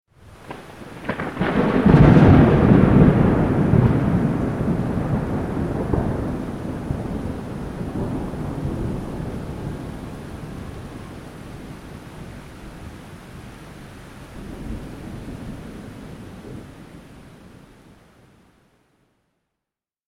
جلوه های صوتی
دانلود صدای طوفان 5 از ساعد نیوز با لینک مستقیم و کیفیت بالا
برچسب: دانلود آهنگ های افکت صوتی طبیعت و محیط دانلود آلبوم صدای طوفان از افکت صوتی طبیعت و محیط